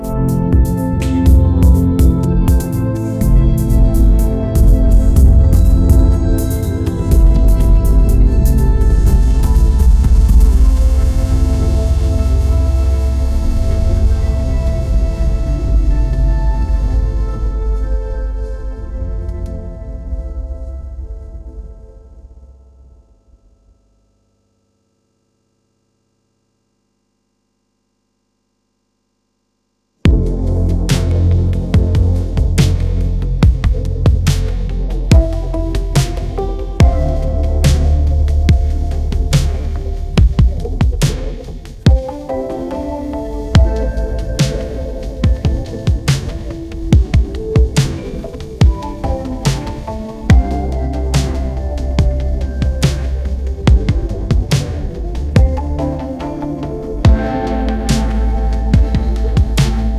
infinifi - infinifi plays gentle lofi music in the background indefinitely